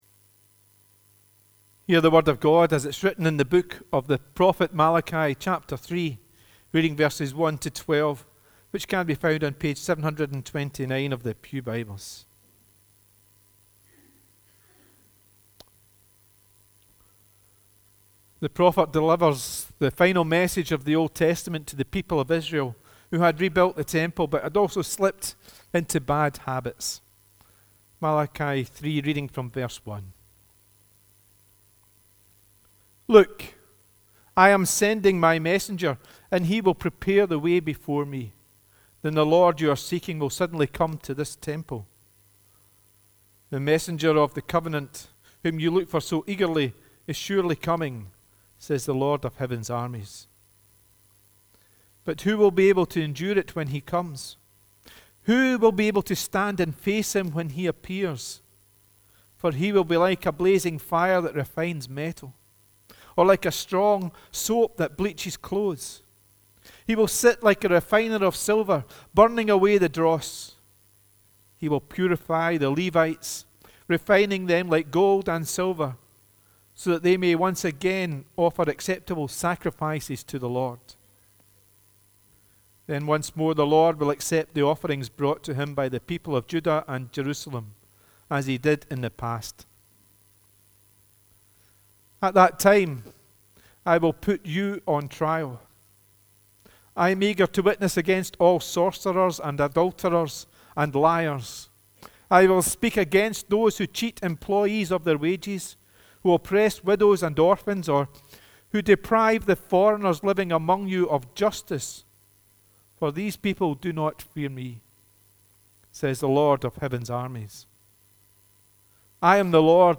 The readings prior to the sermon is Malachi 3:1 – 4:2